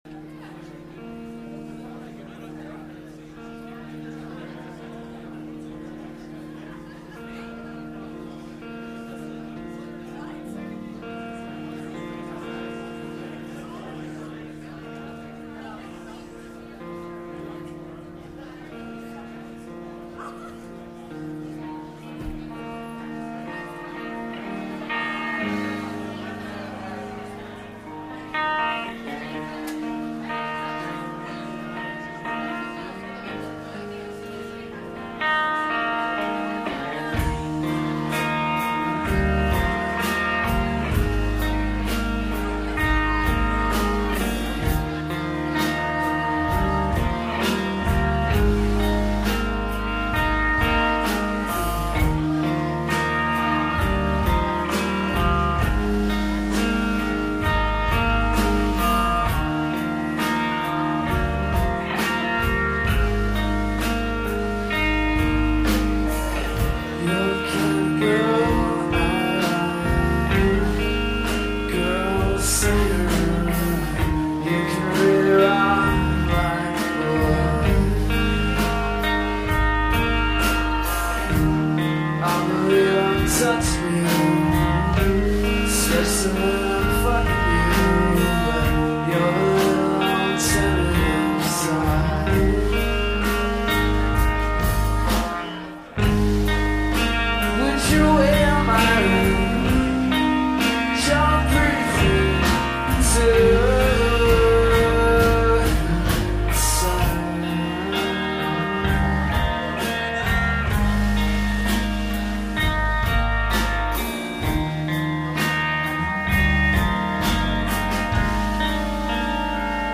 schuba's chicago september 2000